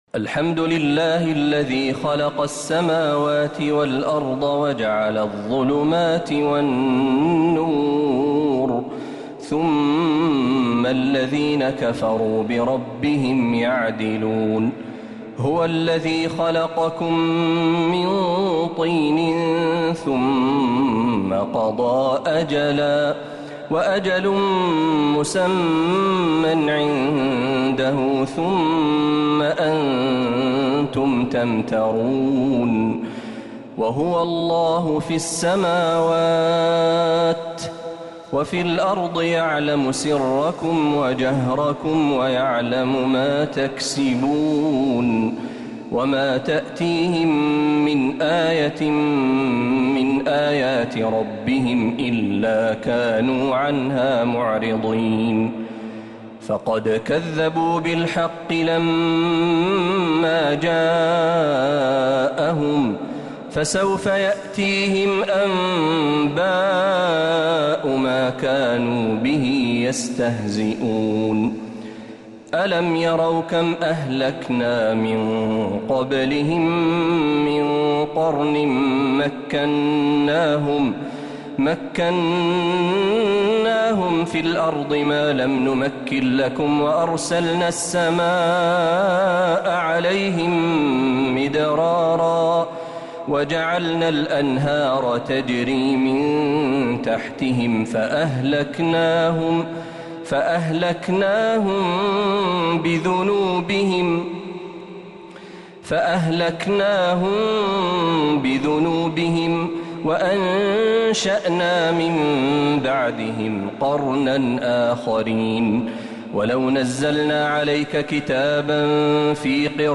سورة الأنعام كاملة من الحرم النبوي